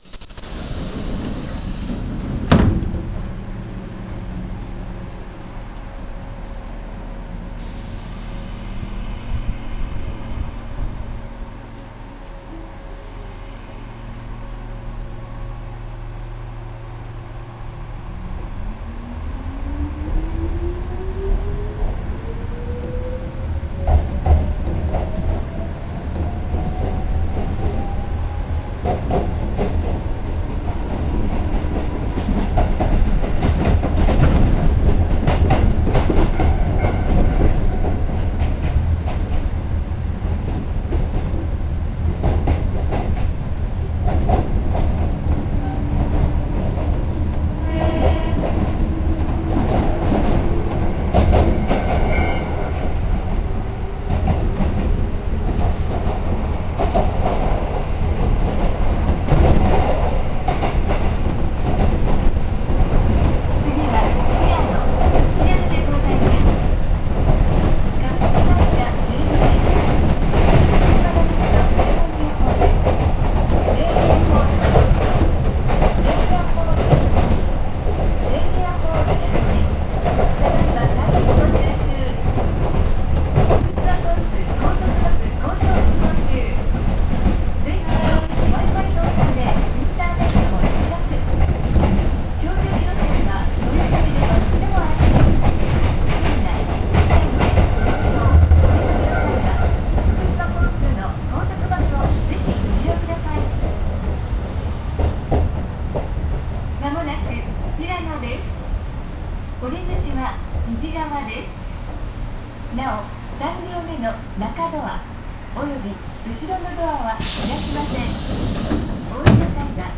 〜車両の音〜
・7000系走行音
【飯坂線】桜水→平野（2分2秒：667KB）
当然ではありますが抵抗制御。東急7000系そのままの筈なので走行音も変わっていないはずです（東急7000系自体に乗った事が無いのではっきりした事は言えませんが）。車掌が乗務していますが、自動放送は設置されています。